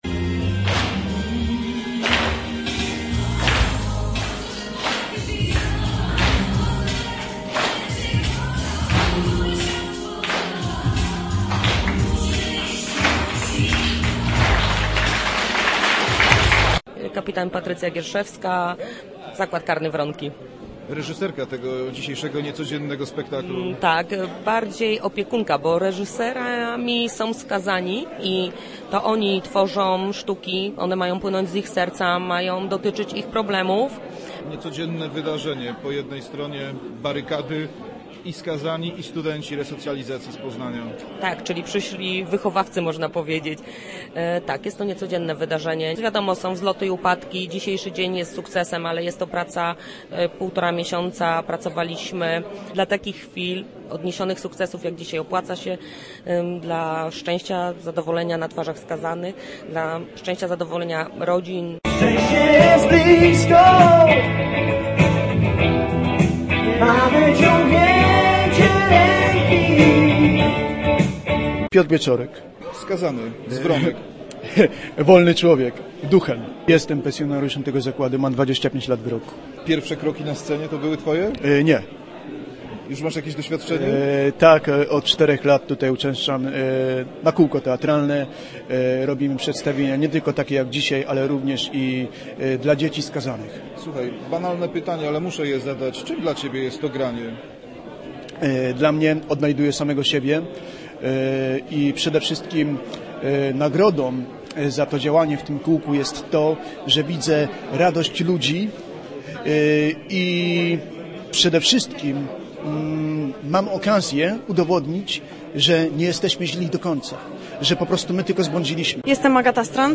Z mikrofonem w ręku był tam też nasz reporter.